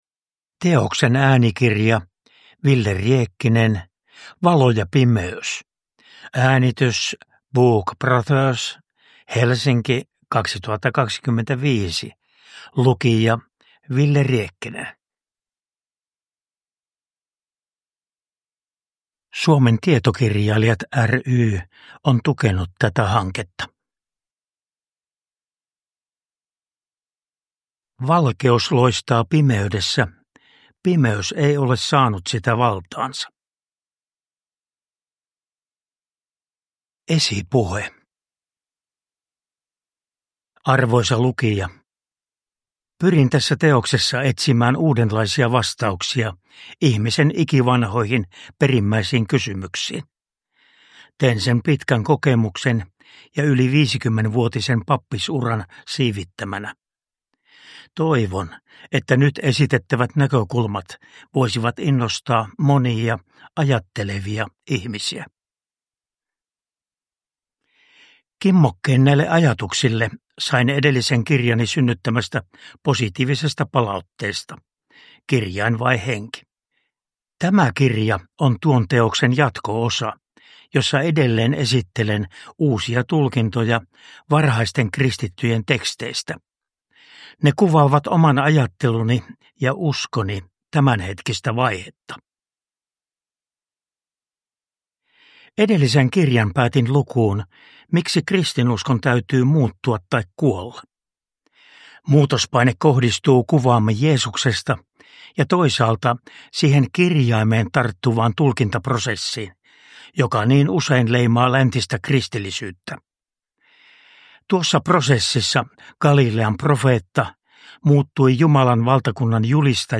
Valo ja pimeys – Ljudbok
Uppläsare: Wille Riekkinen